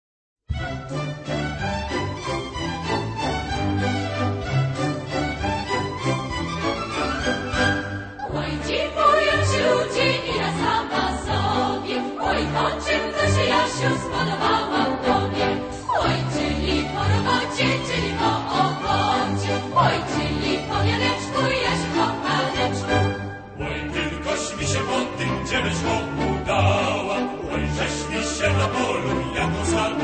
Archival recordings from years 1961-1970